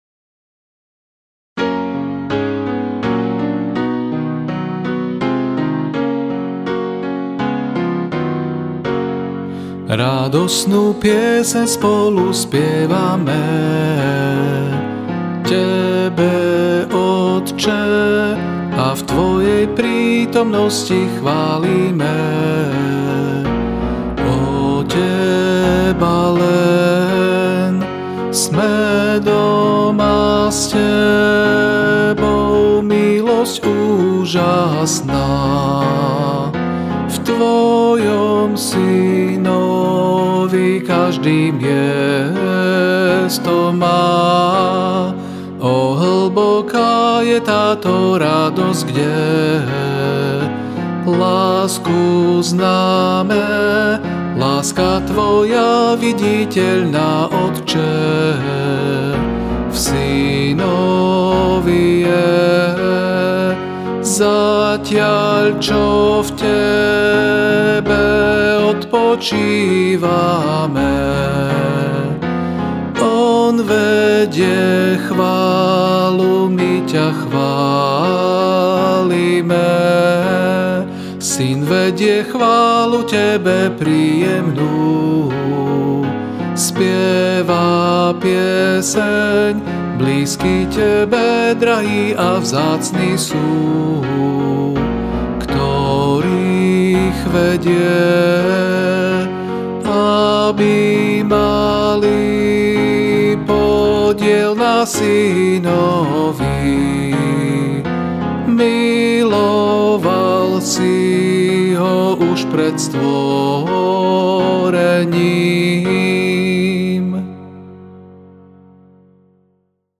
Ab大調